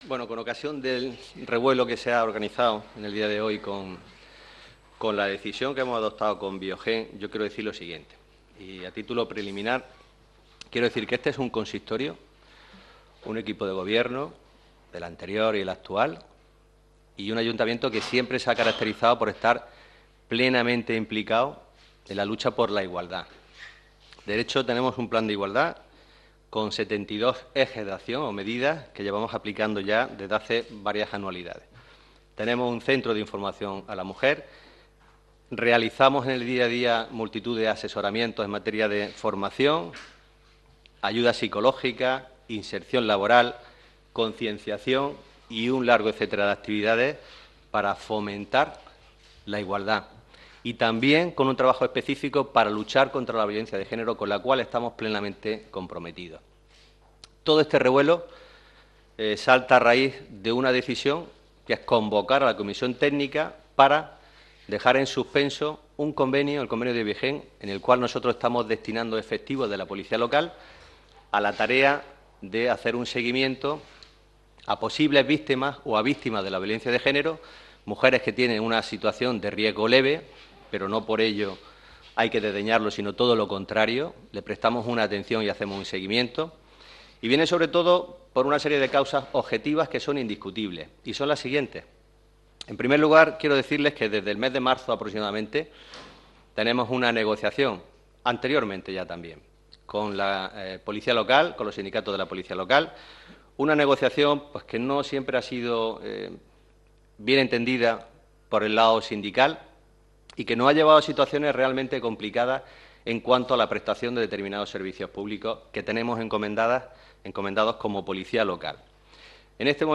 Declaraciones
alcalde_viogen.mp3